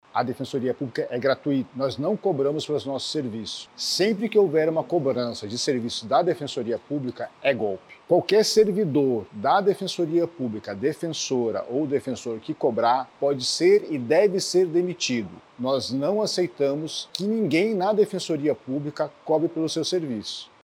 O 2º Subdefensor Público Geral, Marcos Aurélio, lamenta que o nome da Defensoria esteja sendo usado para prejudicar os assistidos e reforça que nenhum procedimento realizado pela instituição envolve qualquer tipo de cobrança.